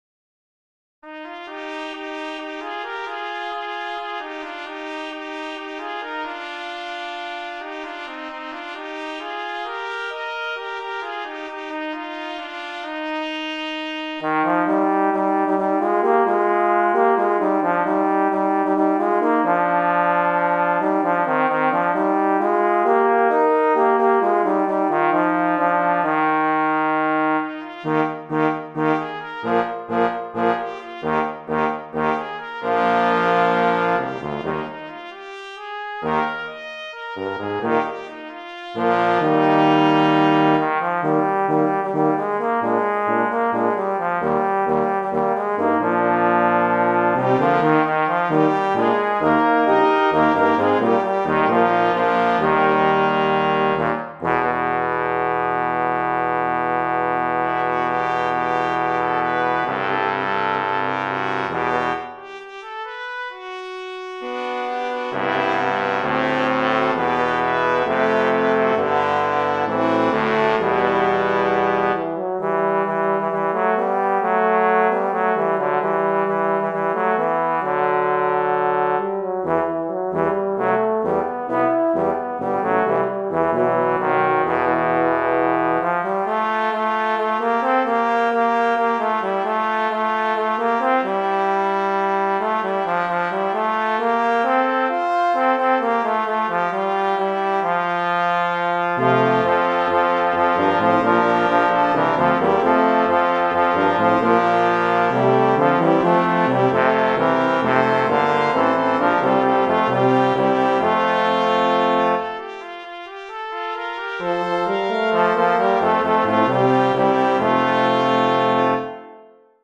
Composer: American Folk Song
Voicing: Brass Quintet